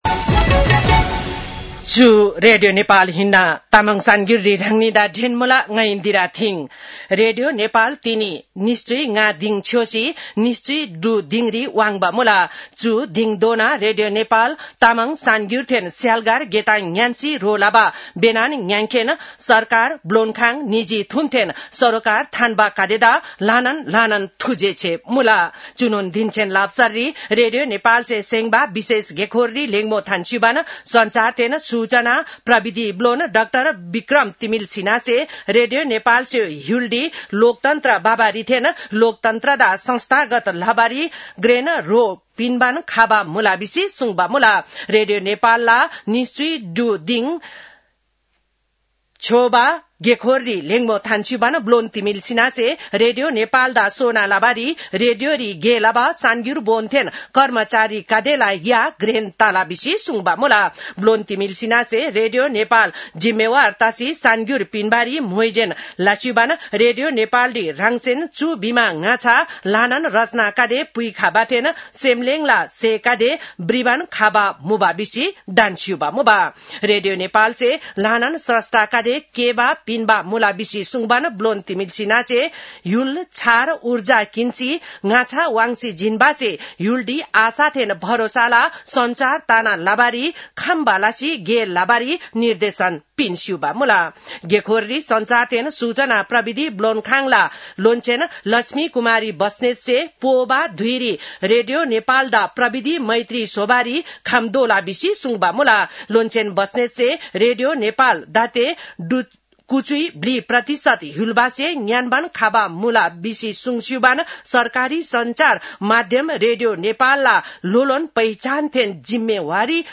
तामाङ भाषाको समाचार : २० चैत , २०८२
Tamang-news-12-20.mp3